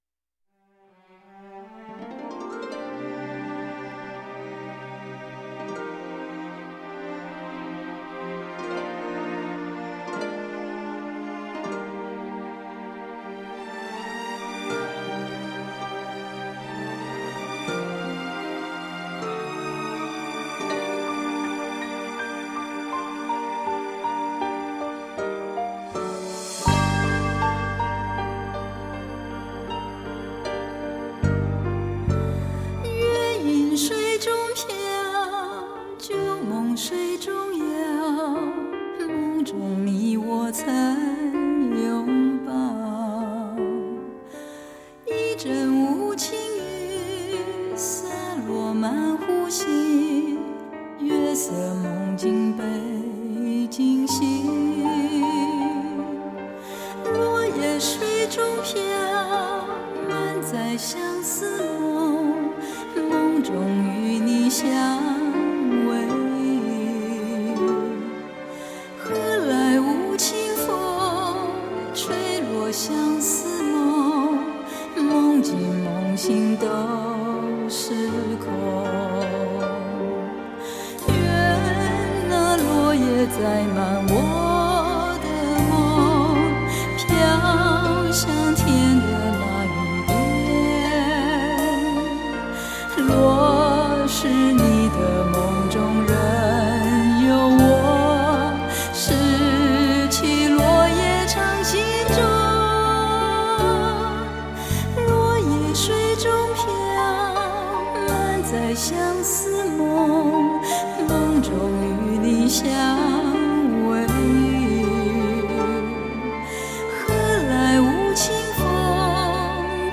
美麗與輕愁纏綿交織